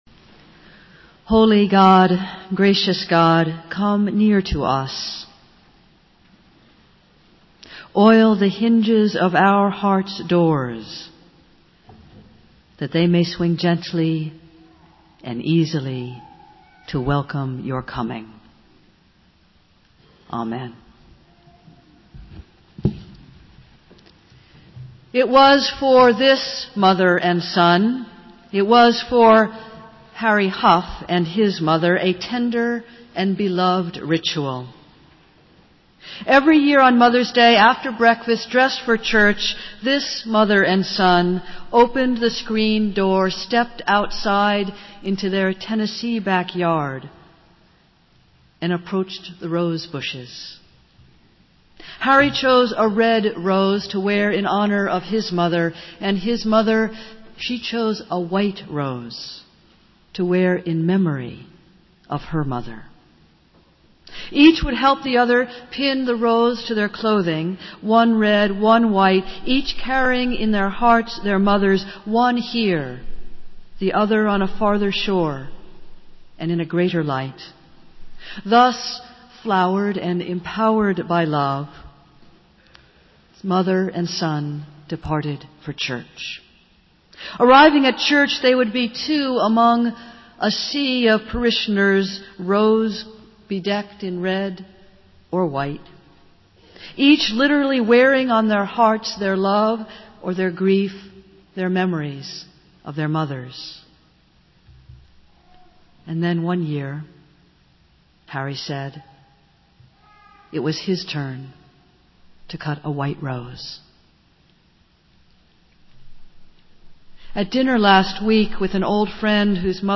Festival Worship - Mother's Day